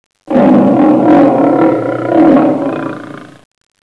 wenn der löwe brüllt
lion_2.wav